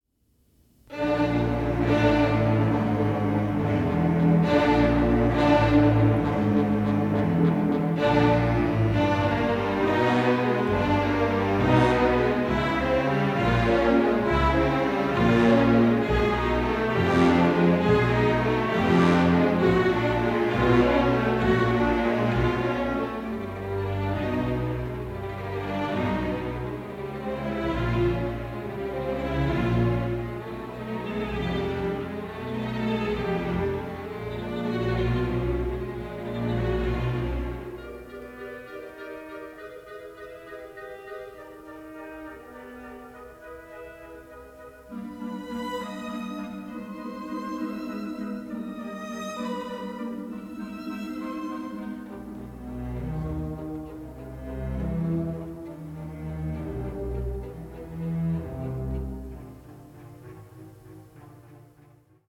Analog Multi-Track Stereo Remix